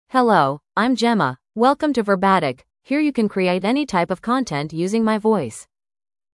GemmaFemale English AI voice
Gemma is a female AI voice for English (United States).
Voice sample
Female
Gemma delivers clear pronunciation with authentic United States English intonation, making your content sound professionally produced.